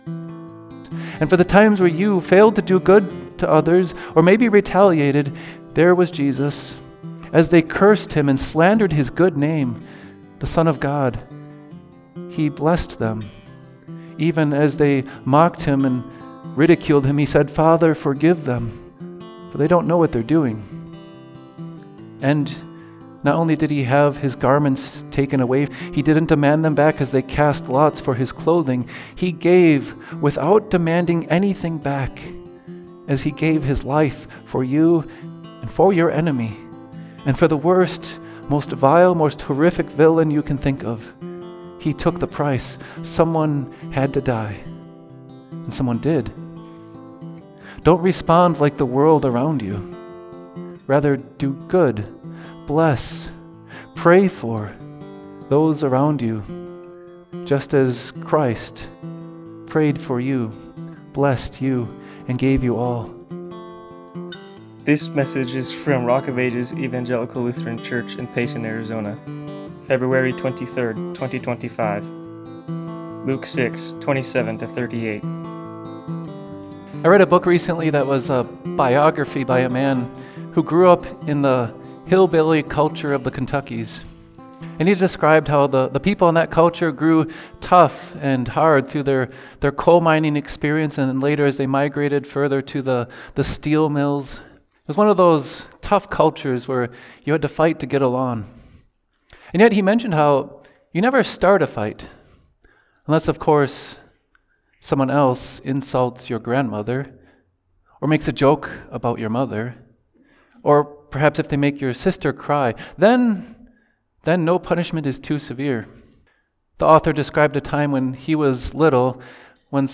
Epiphany Moments 7) Evil is Overcome With Good Sermons Luke 6:27–38 ● 2025-02-23 ● Series: Epiphany Moments ● Listen Podcast: Play in new window | Download Subscribe: Apple Podcasts | Spotify | Pandora | RSS